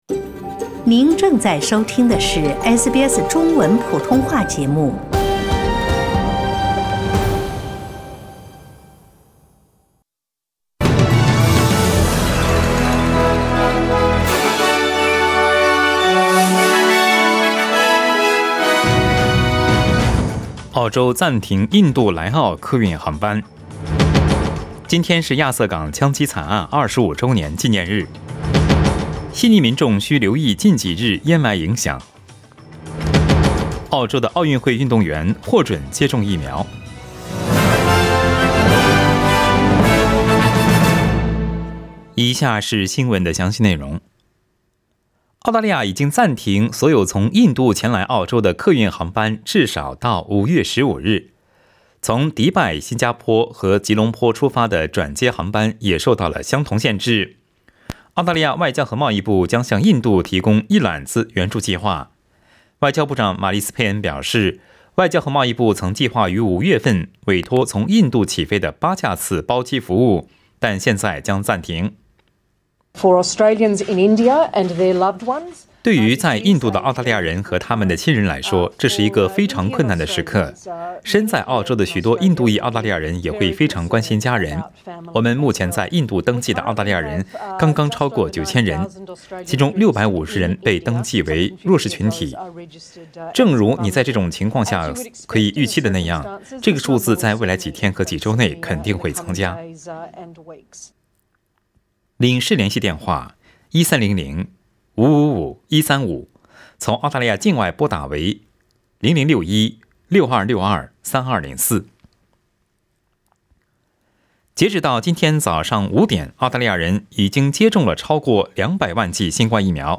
SBS早新聞 （4月28日）
SBS 普通話電台